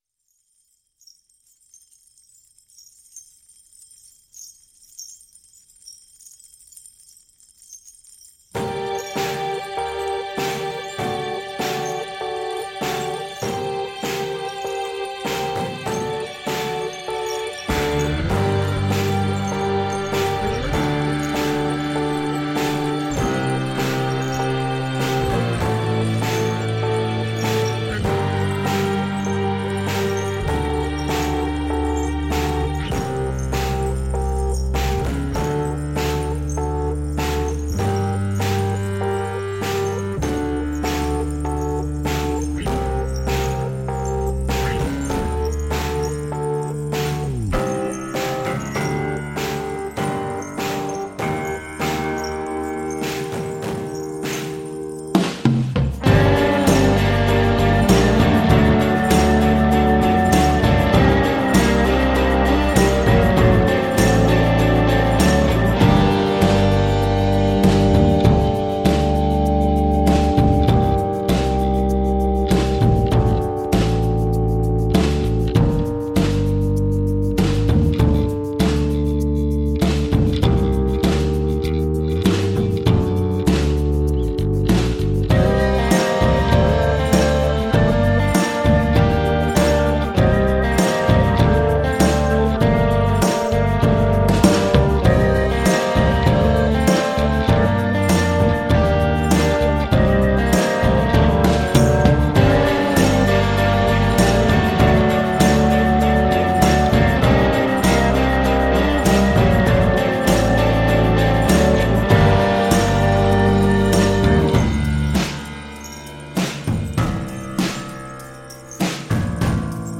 A fresh new approach to lush indie pop.